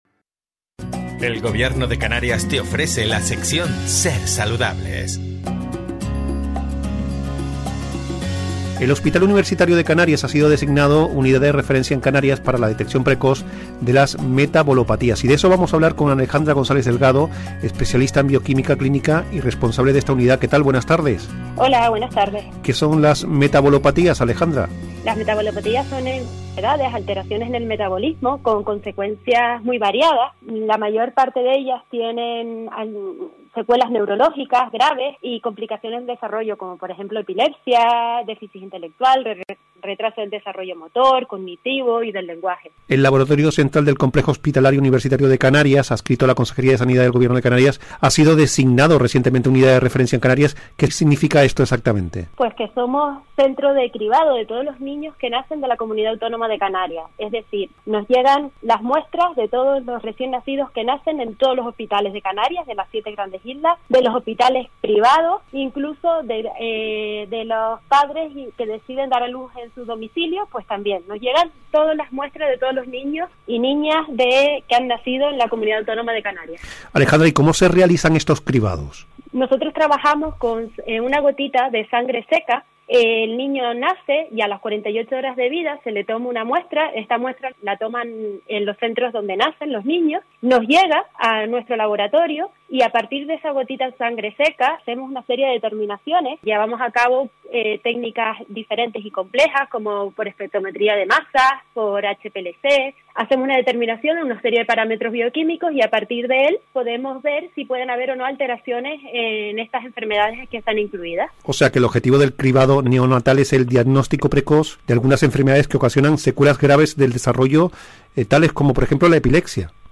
Entrevista Cribado Neonatal